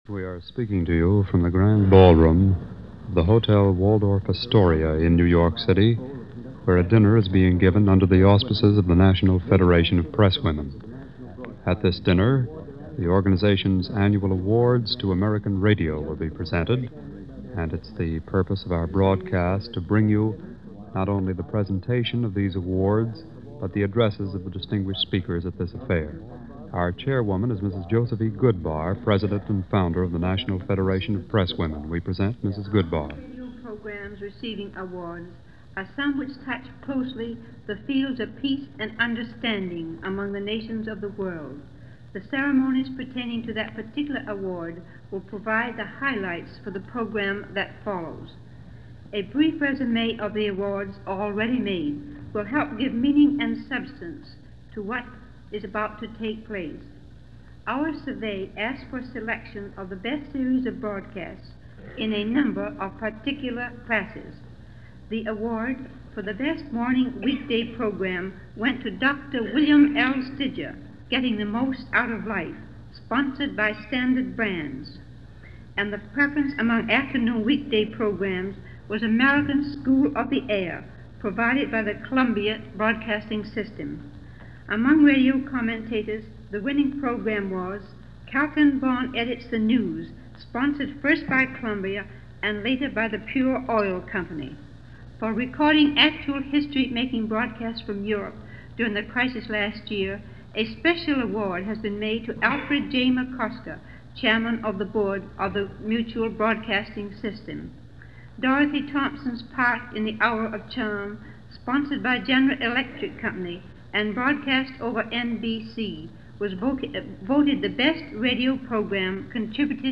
This Awards banquet was in honor of Radio broadcasting – at the time just flexing its muscles in the years just prior to World War 2.